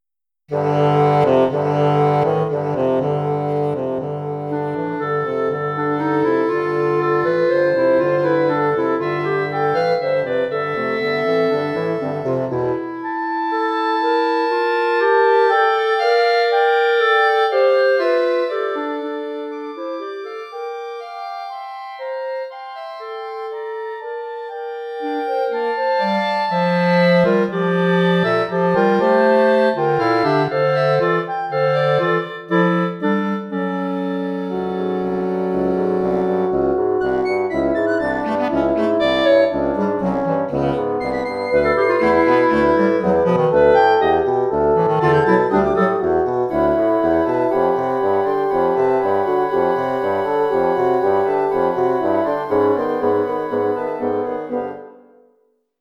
Audio files, Woodwinds: